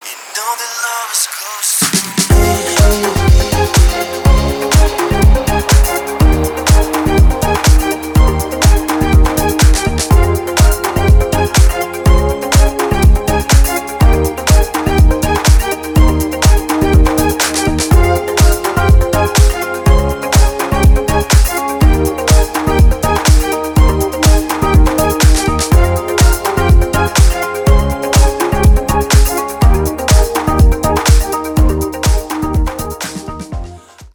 мужской голос
Electronic
спокойные
house
Стиль: house